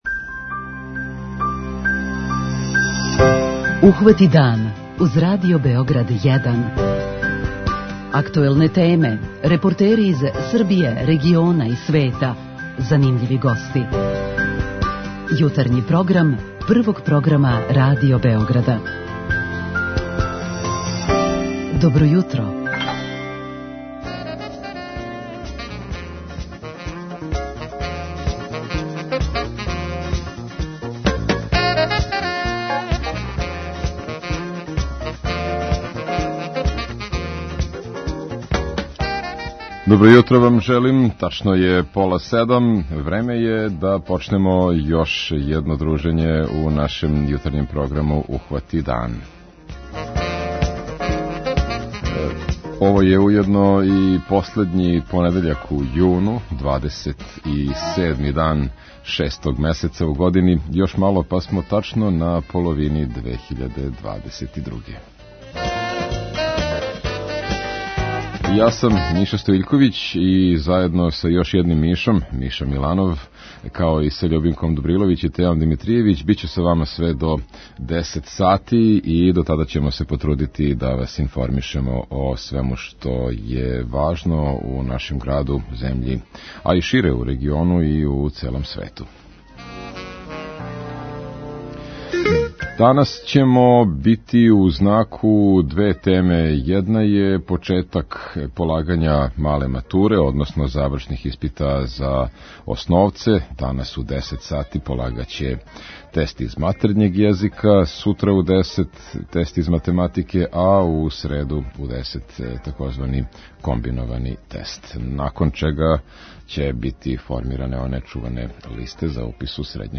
О овој теми претходно ћемо разговарати и са слушаоцима у нашој редовној рубрици 'Питање јутра'.